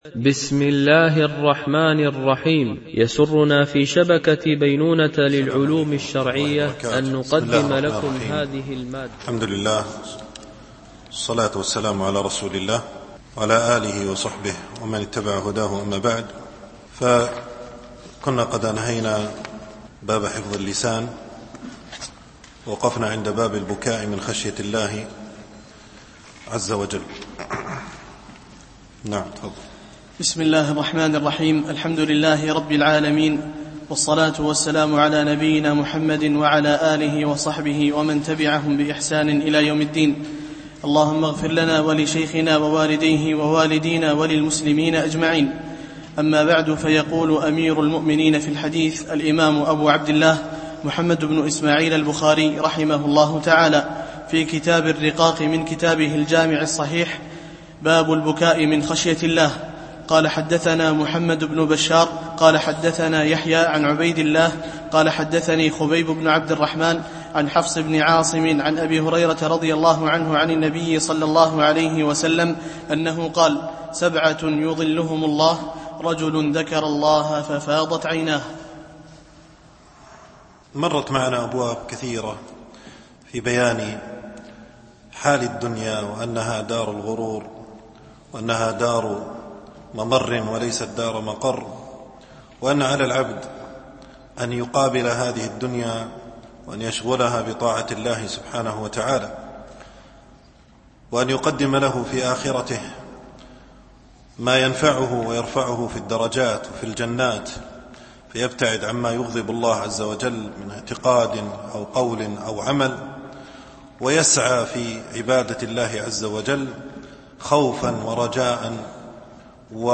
الدورة العلمية
MP3 Mono 22kHz 32Kbps (CBR)